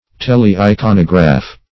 Search Result for " tele-iconograph" : The Collaborative International Dictionary of English v.0.48: Tele-iconograph \Tel`e-i*con"o*graph\, n. [Gr. th^le far + iconograph.] 1.
tele-iconograph.mp3